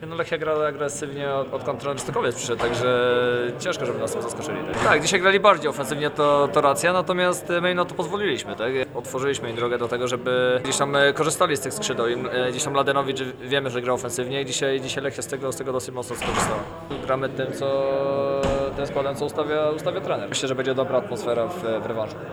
Posłuchaj, co do powiedzenia miał Kamil Wilczek po meczu z Lechią: